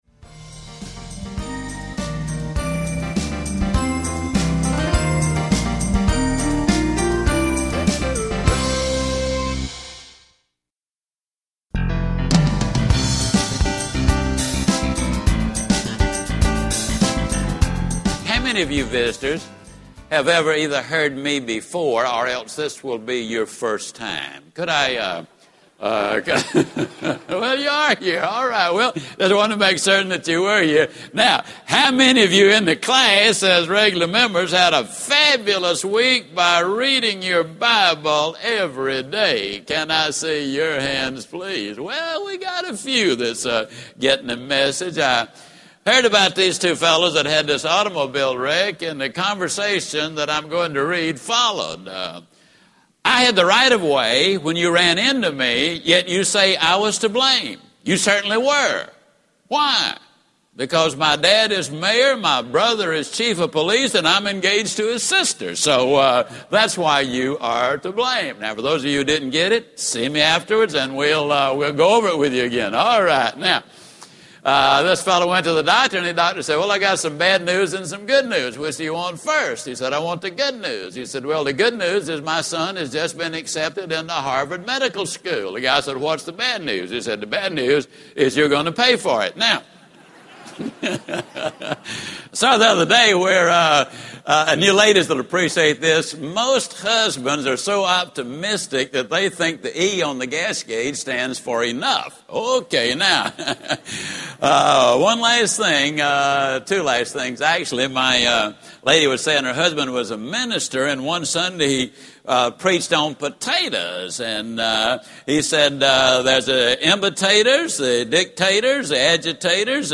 Winning with a Balanced Goals Program Audiobook
Narrator
Zig Ziglar
7.75 Hrs. – Unabridged